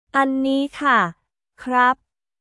アンニー クラップ／カー